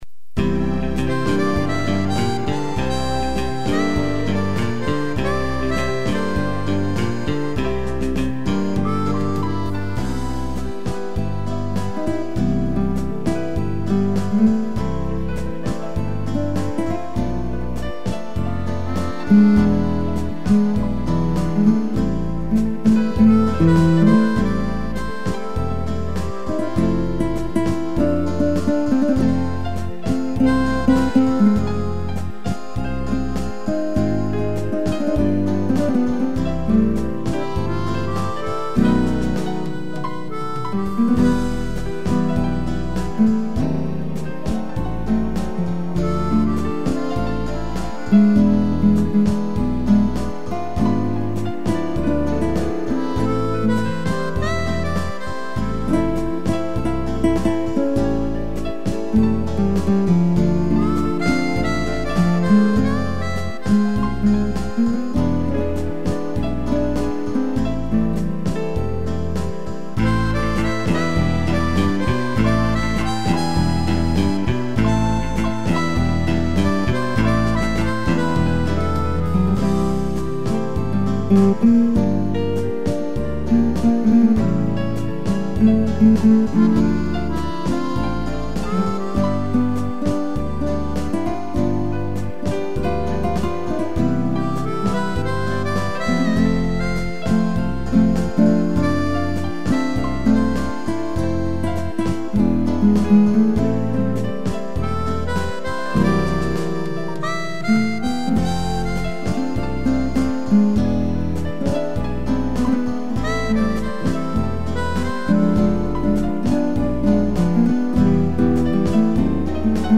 piano e gaita
(instrumental)